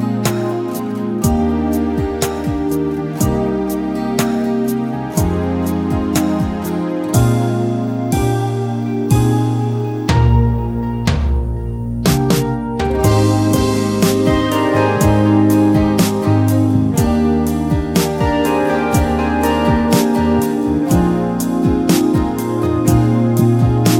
Minus Lead Guitar Pop (1970s) 4:12 Buy £1.50